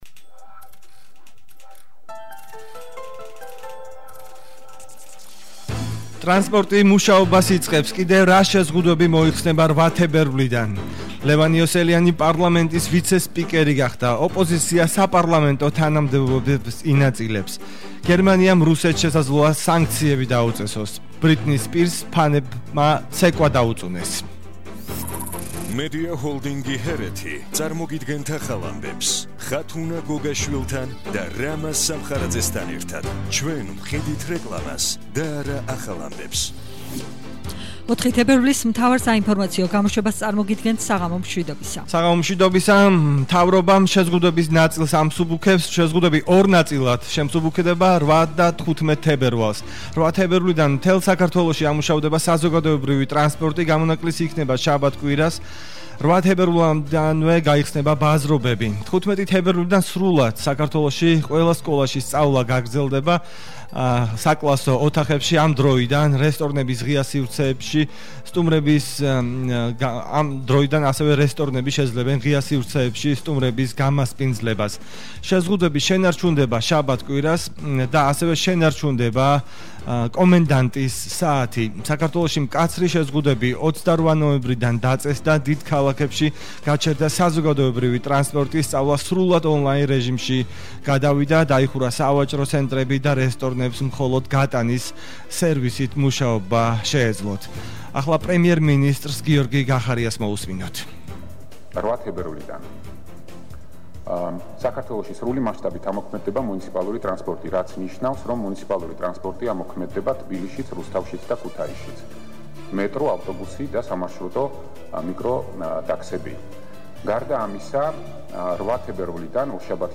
მთავარი საინფორმაციო გამოშვება –04/02/21 – HeretiFM